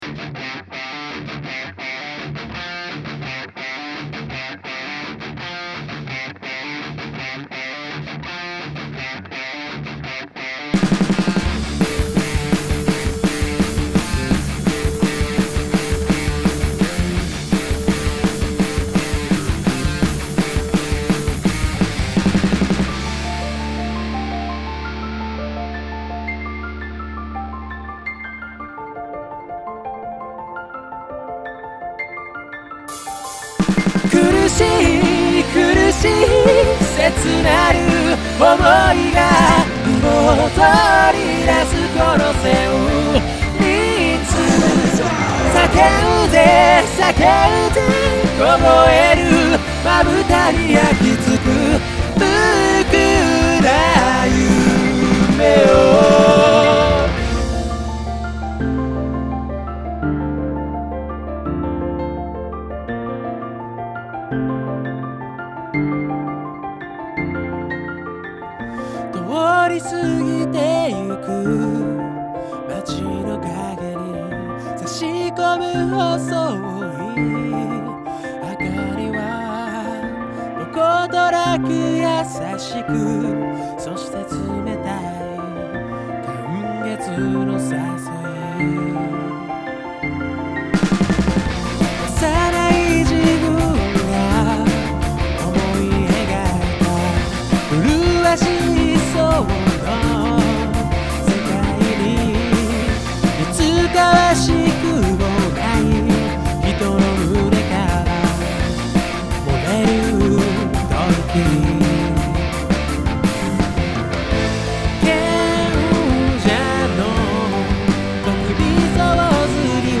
辛い、苦しい、心揺れる激しい思いを描いたロックチューン